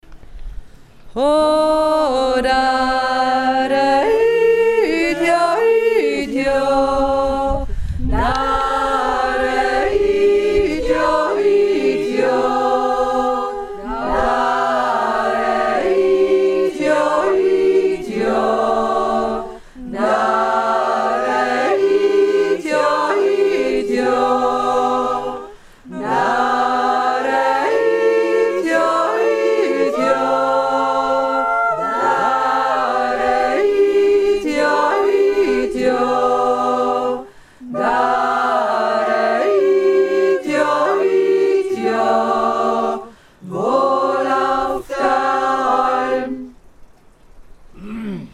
und so jodelten wir freiraus:
b-uerinnenjodler-2.mp3